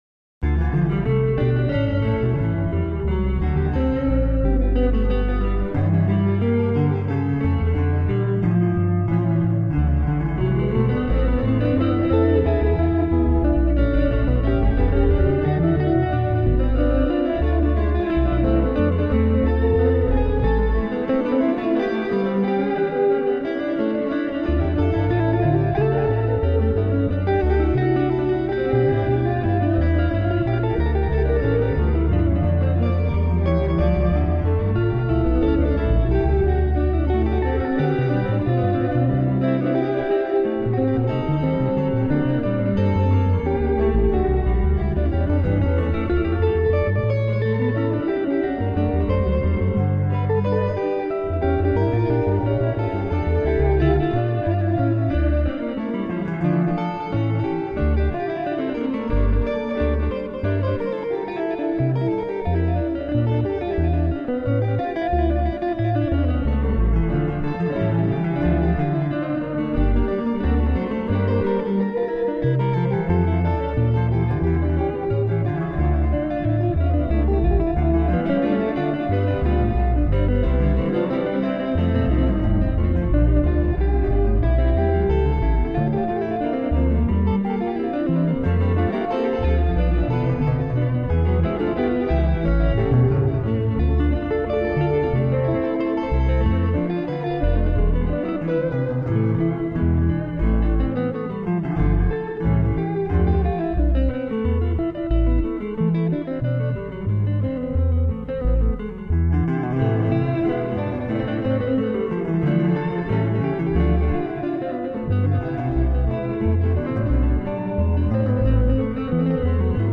Concerto for Two Violins in D minor, BWV1043